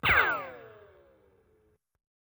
shot.wav